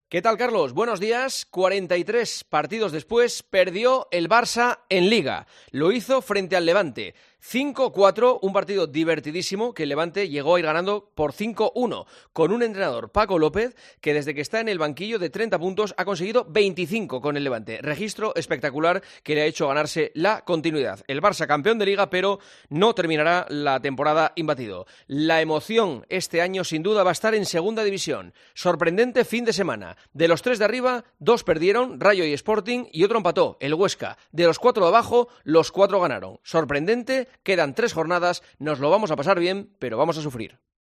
Escucha el comentario del director de 'El Partidazo de COPE', Juanma Castaño, en 'Herrera en COPE'